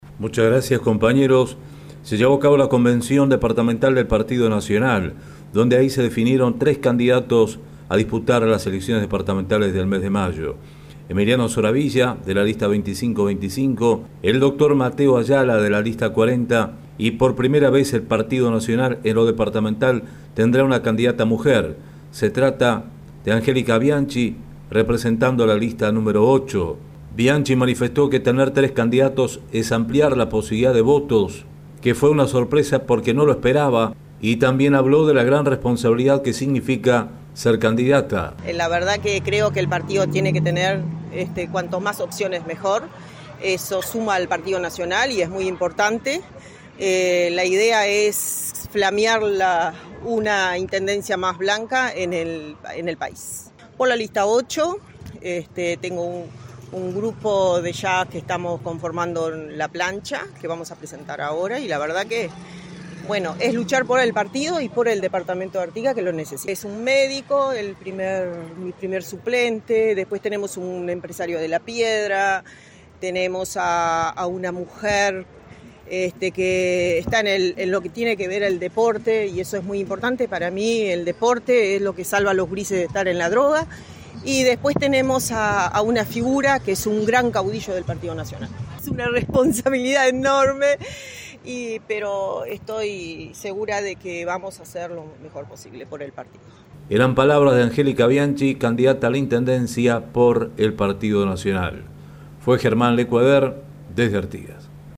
Escuchar informe del corresponsal